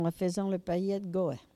Mots Clé foin, fenaison ; Localisation Barbâtre
Catégorie Locution